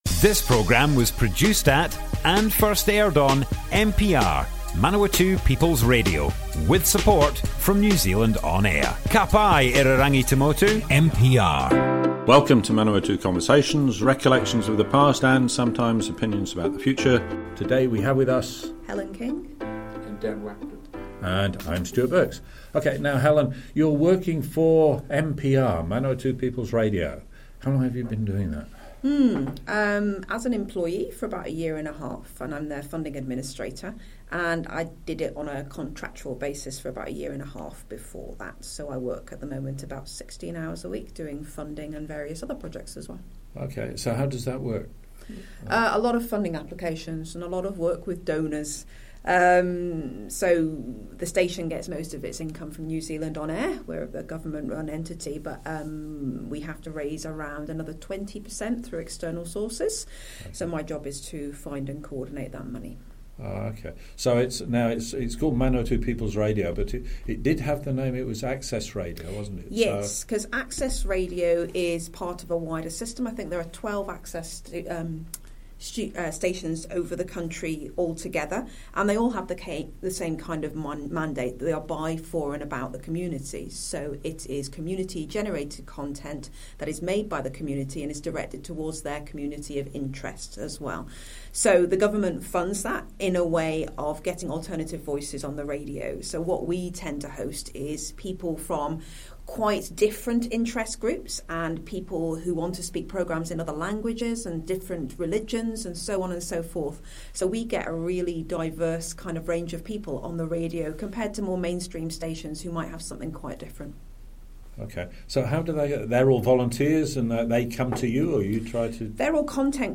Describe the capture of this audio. Manawatu Conversations More Info → Description Broadcast on Manawatu People's Radio, 11th May 2021.